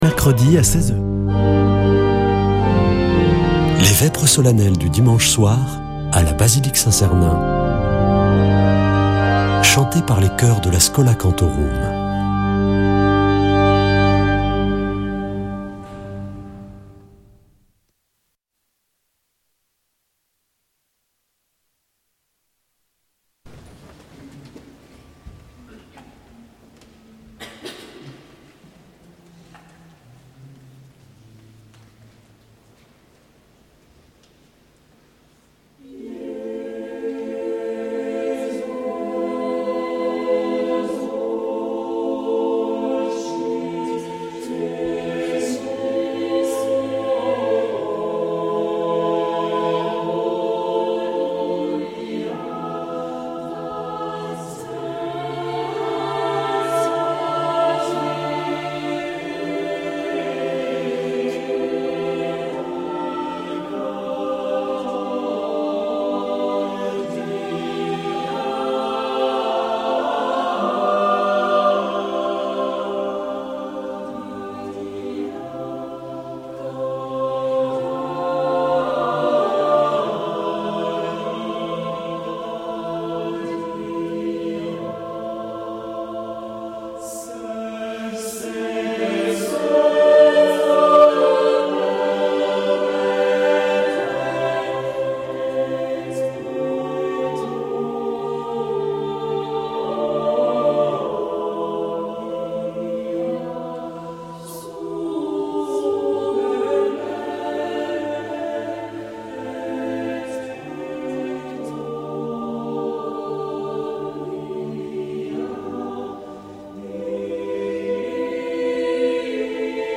Accueil \ Emissions \ Foi \ Prière et Célébration \ Vêpres de Saint Sernin \ Vêpres de Saint Sernin du 06 avr.
Une émission présentée par Schola Saint Sernin Chanteurs